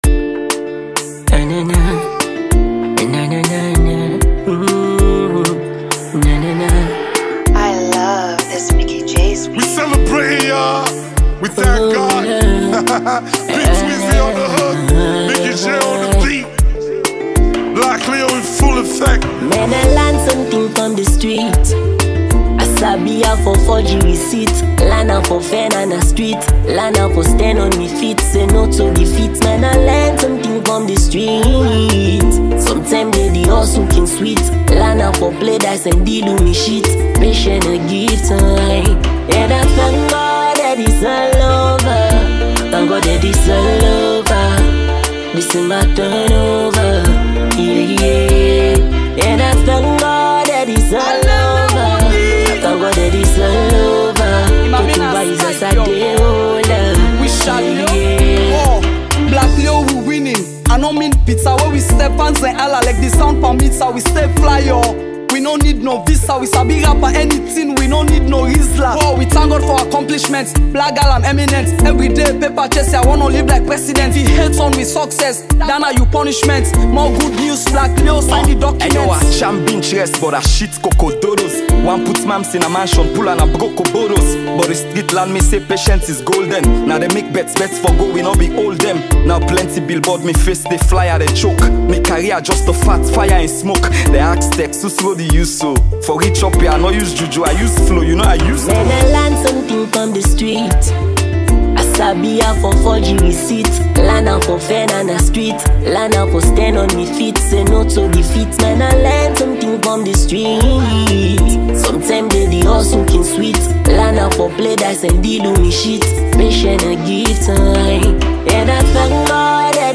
17 solid Hip Hop tracks